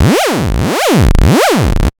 FX
Squelch-Virus.wav